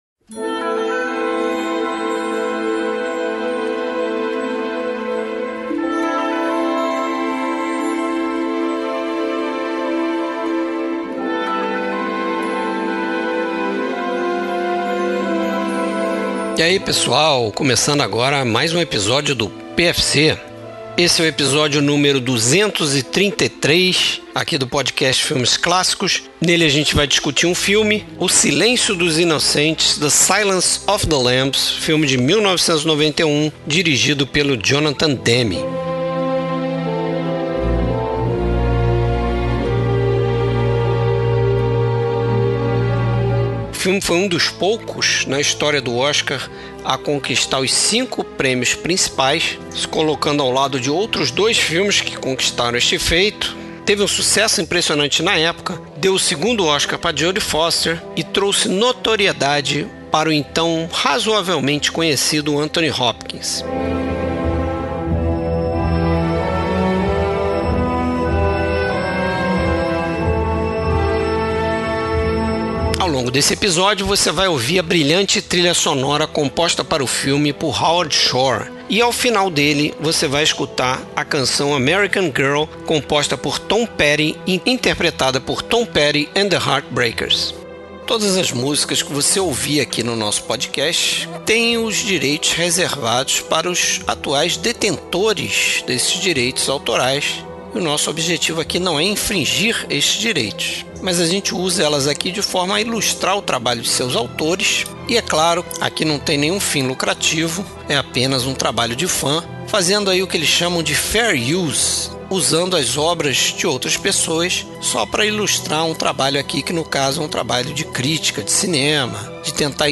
Três founding fathers do Podcast Filmes Clássicos se juntam para conversar sobre um dos filmes americanos mais aclamados dos anos 90.